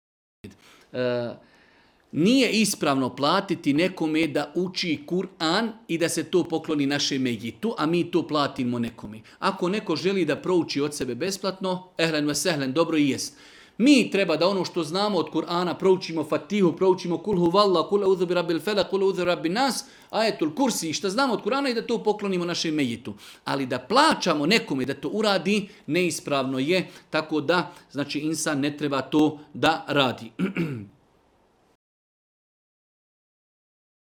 video predavanju